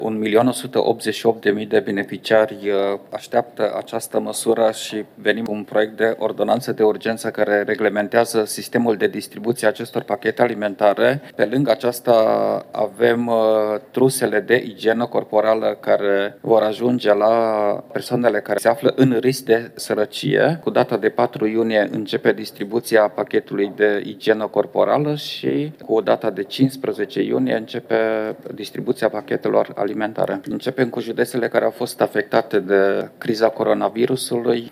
• Marcel Boloș, ministrul Fondurilor Europene